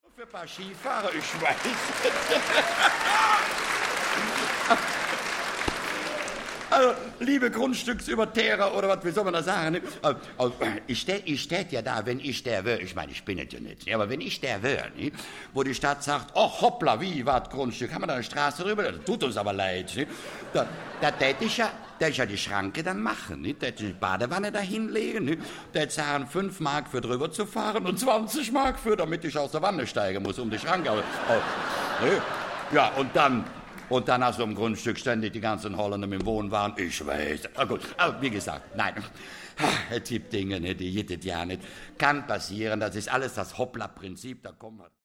Konrad Beikircher (Sprecher)
Schlagworte AUDIO/Belletristik/Comic, Cartoon, Humor, Satire/Humor, Satire, Kabarett • Audio-CD • Hörbücher • Hörbücher; Humor/Comedy (Audio-CDs) • Hörbuch; Humor/Comedy • Hörbuch; Humor/Comedy (Audio-CDs) • Humor/Comedy (Audio-CDs) • Lebensstrategie • Rheinland • Überlebensrezepte